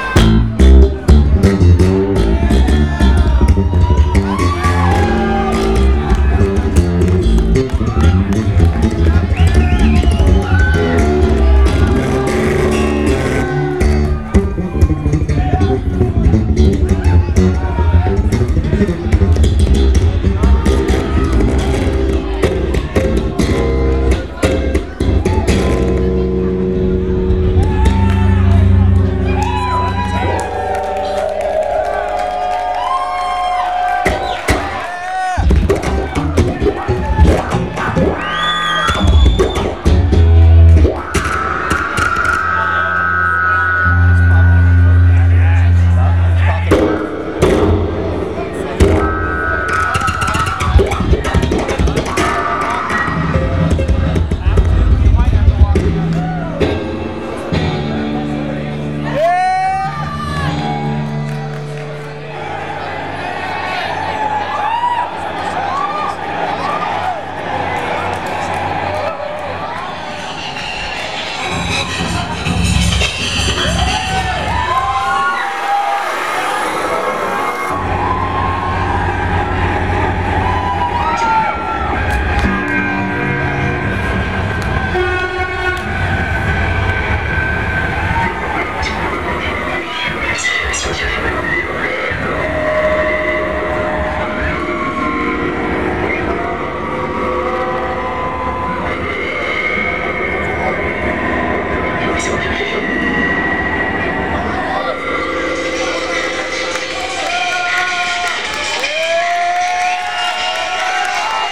Drill & bass
location Phoenix, Arizona, USA venue Old Brickhouse Grill